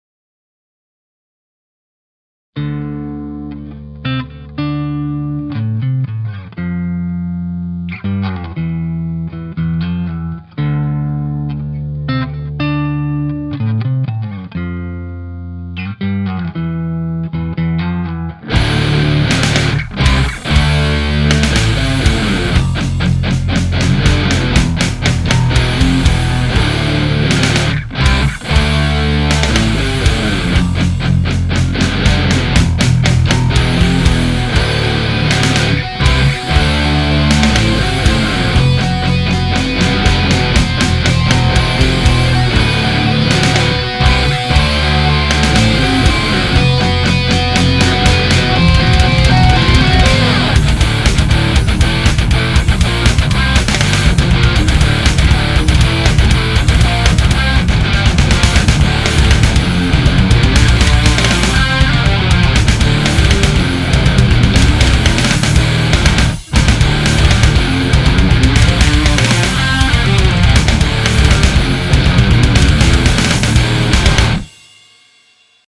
Als Box kam eine Marshall 4x12 mit Greenbacks zum Einsatz und abgenommen wurde mit einem SM57 direkt in den PC. Die ersten vier Samples sind absolut unverändert, beim Heavy-Sample habe ich erstmal vier Spuren gedoppelt eingespielt und ein bisschen mit EQ usw. nachbearbeitet, um ungefähr so Bedingungen zu erzeugen, wie man sie heute bei CDs vorfindet.
Heavy
Das Intro und das Overdub mit der reingefadeten Gitarre sind immer die selben!
heavy_02.mp3